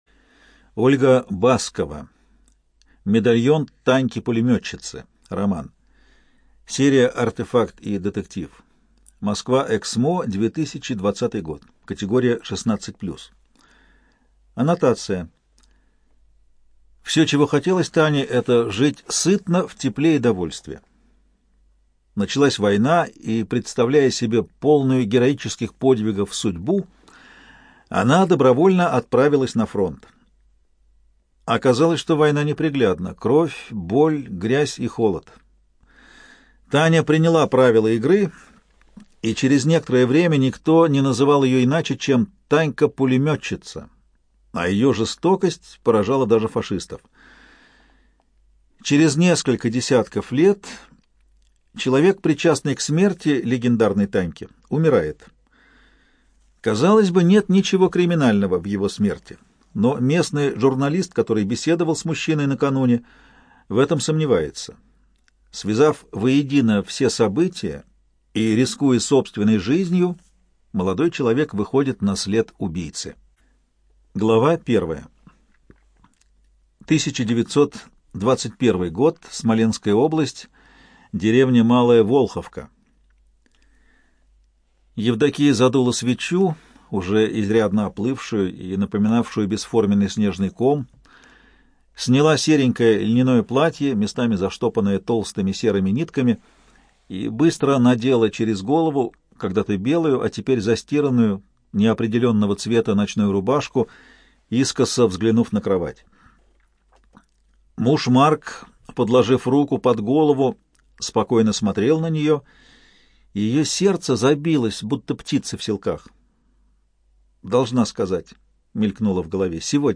Студия звукозаписиЛогосвос